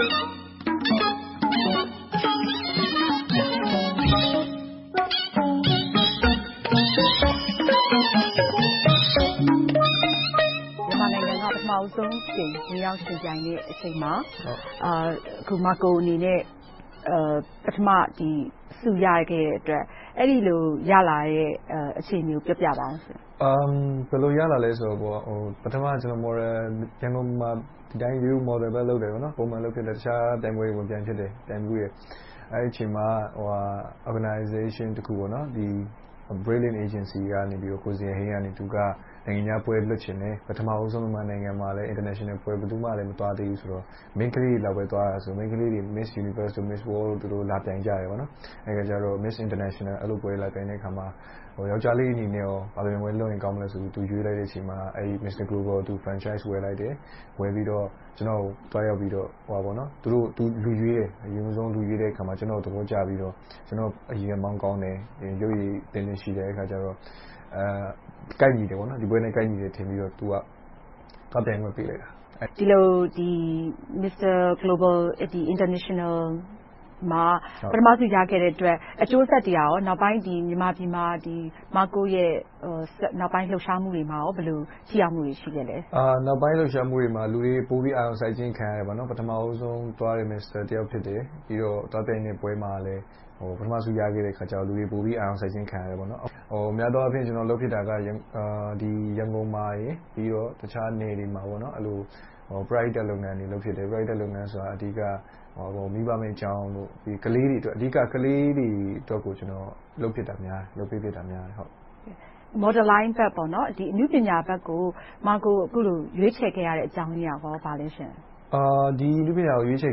တွေ့ဆုံမေးမြန်း